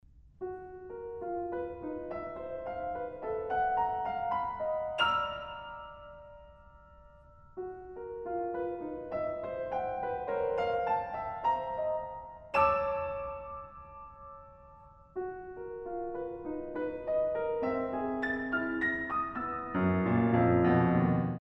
in E-Flat Minor